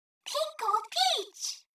Audio.svg Mario Kart 8 - "Pink Gold Peach!"- When unlocked (Samantha Kelly, 2014)